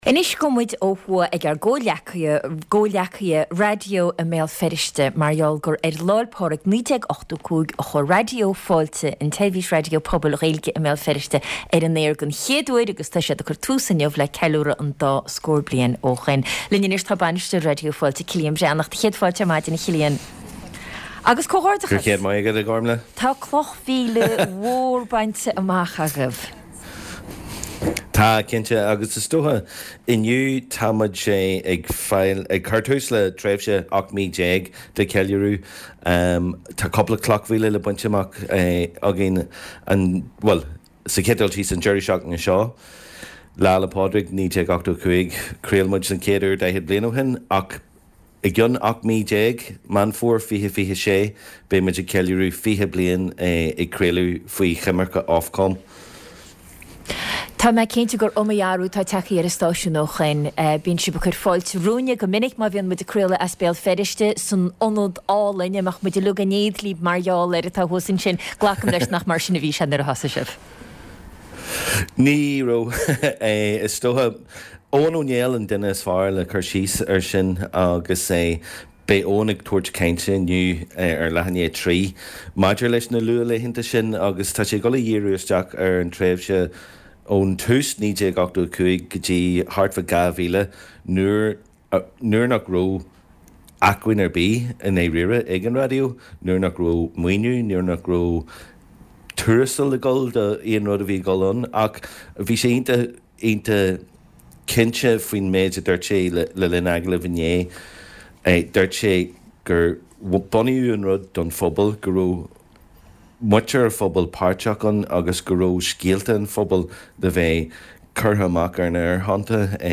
Clár cúrsaí reatha agus nuachta náisiúnta, idirnáisiúnta agus áitiúil le tuairisc spóirt agus tuairisc ar na nuachtáin.